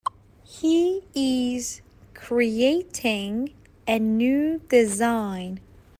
تلفظ با سرعت‌های مختلف